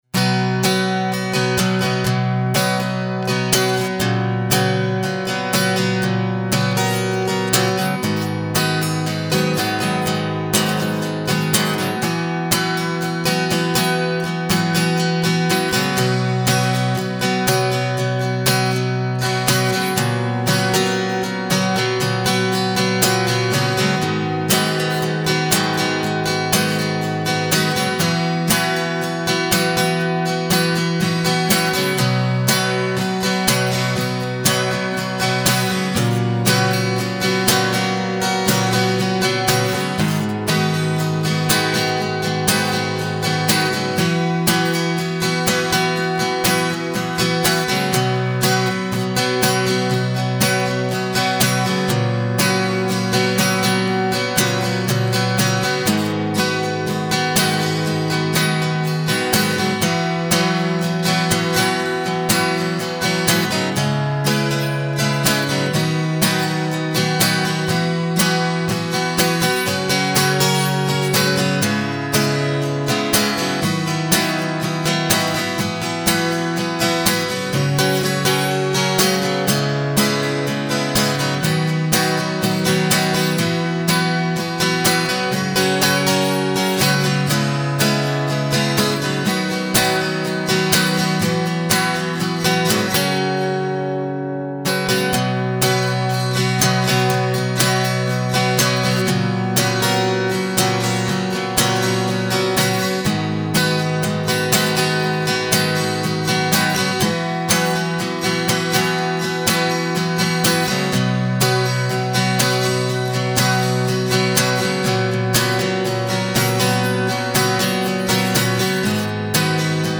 acoustic-indie-pop
acoustic-indie-pop.mp3